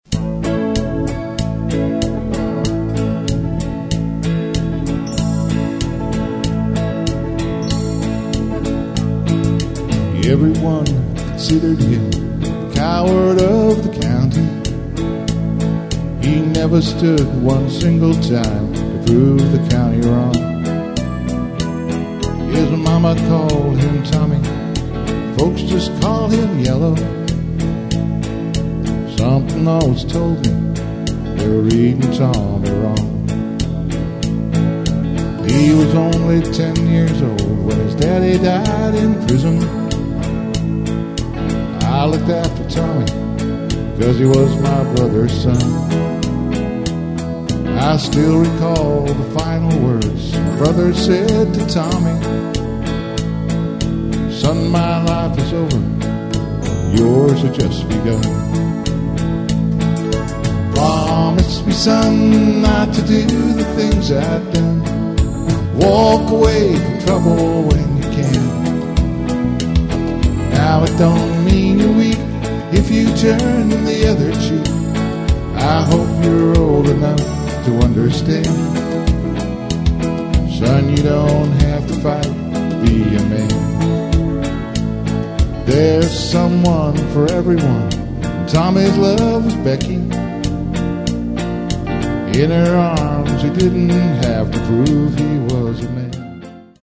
Quicksteps / Jives